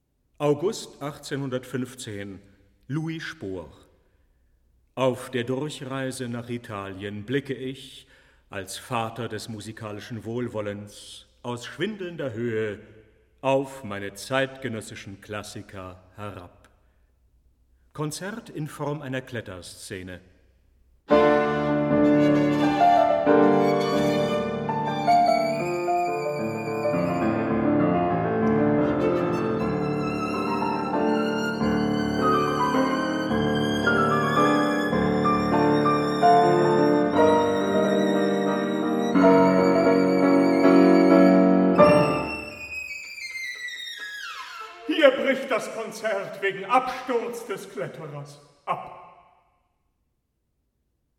Albert Moeschinger: Louis Spohr, 1815 (violin, piano)